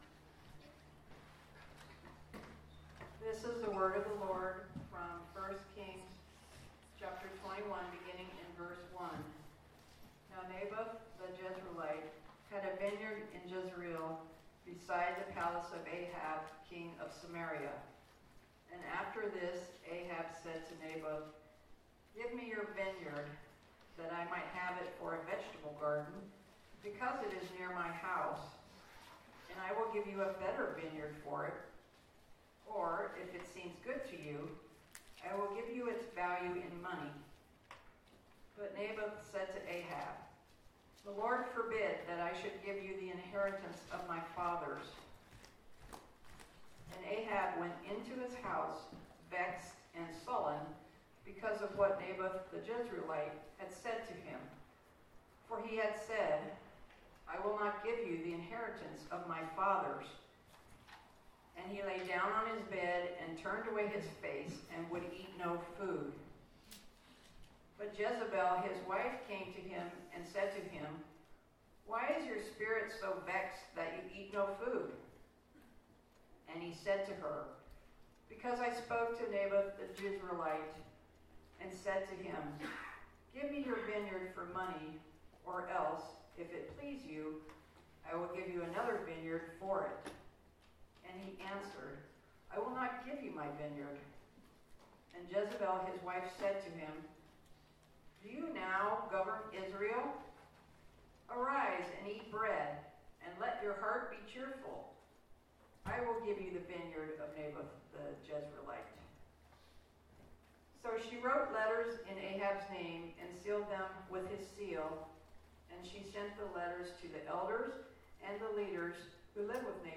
Passage: 1 Kings 21:1-29 Service Type: Sunday Morning